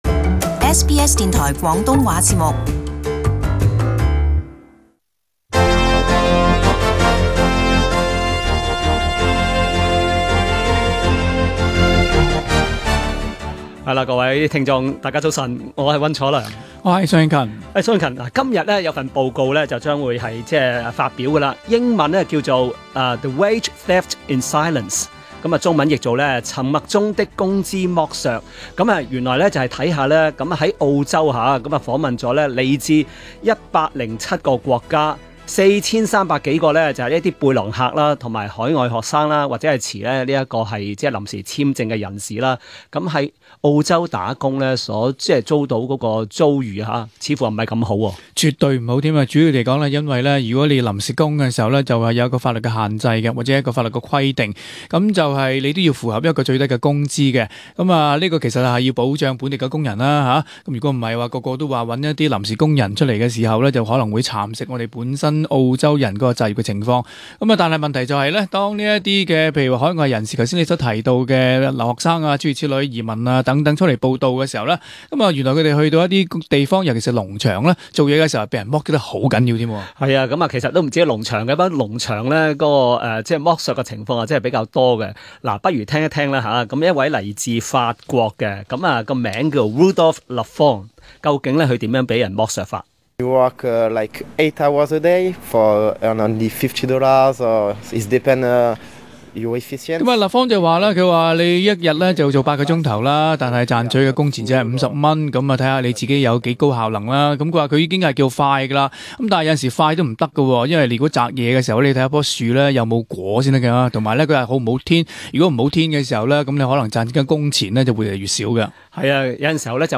【時事報導】 背包客與留學生被「盜」工資高達 10 億元